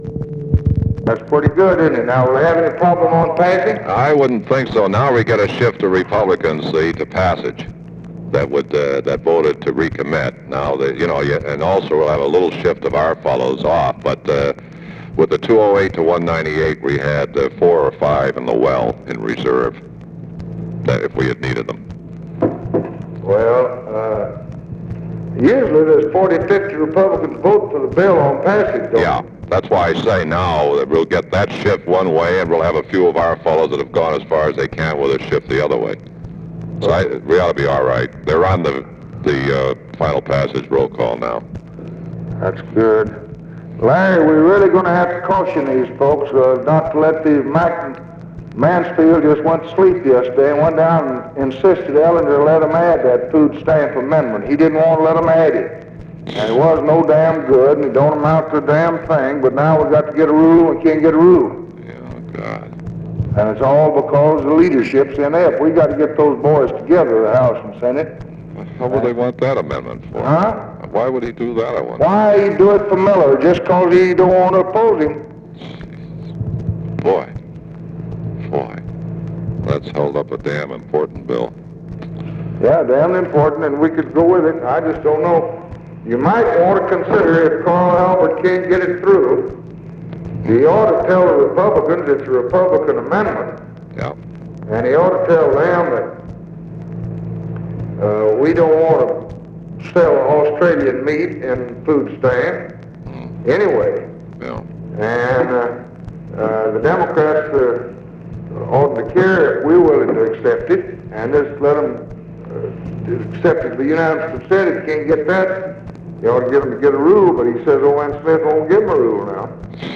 Conversation with LARRY O'BRIEN, July 1, 1964
Secret White House Tapes